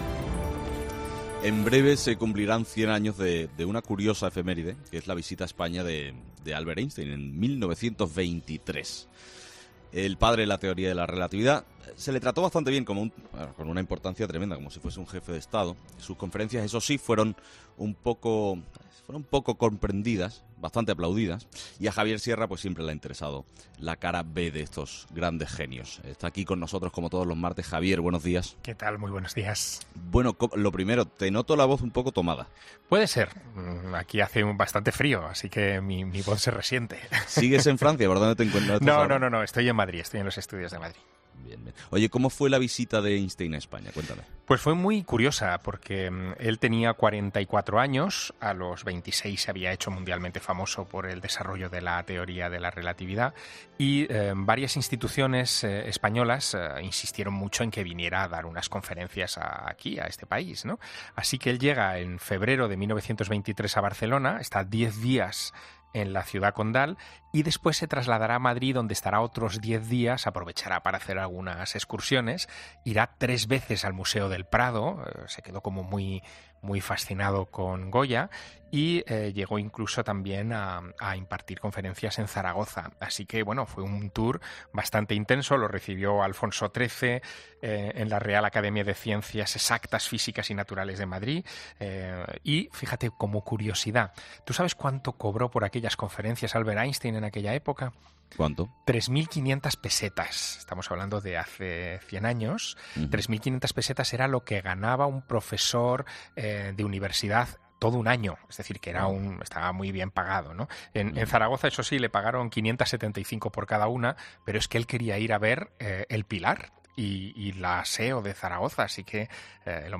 Javier Sierra, escritor y periodista, cuenta en 'Herrera en COPE' las curiosidades más misteriosas de uno de los físicos más grandes de la historia